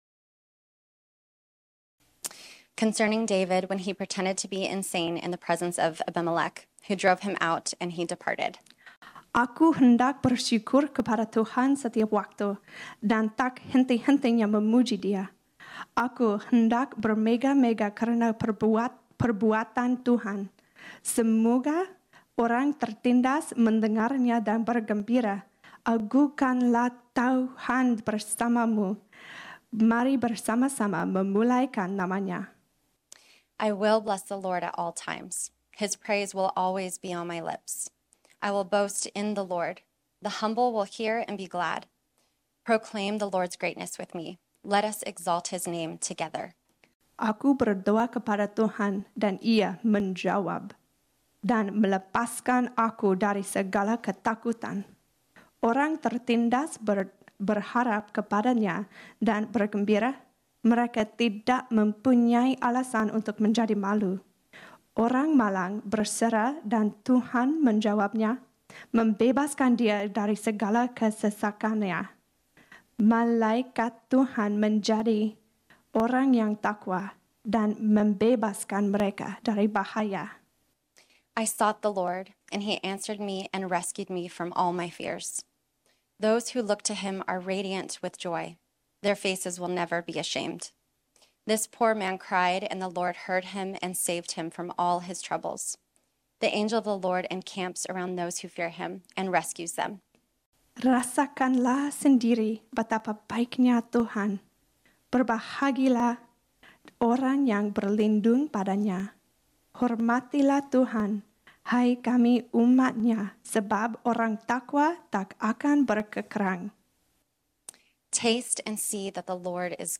This sermon was originally preached on Sunday, June 4, 2023.